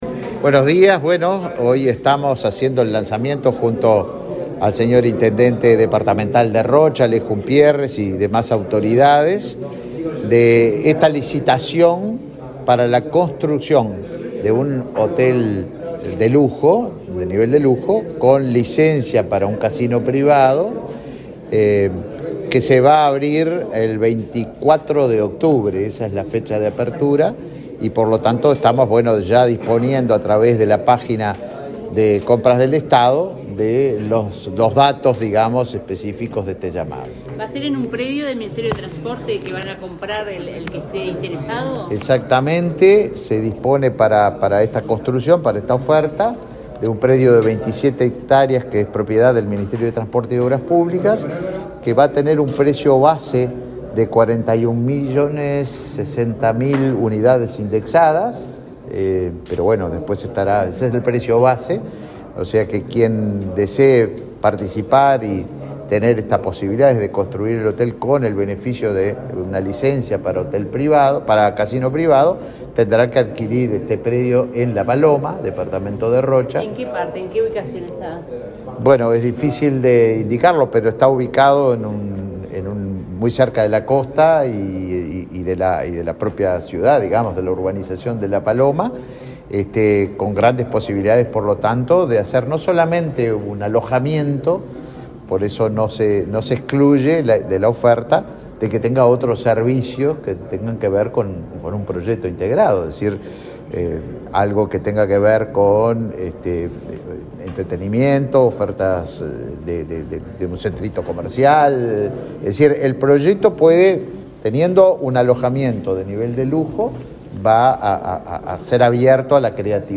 Declaraciones del ministro de Turismo, Tabaré Viera
Declaraciones del ministro de Turismo, Tabaré Viera 21/08/2023 Compartir Facebook X Copiar enlace WhatsApp LinkedIn El ministro de Turismo, Tabaré Viera, dialogó con la prensa luego del acto en el que brindó detalles de la licitación pública internacional para la construcción de un hotel y casino en La Paloma, departamento de Rocha.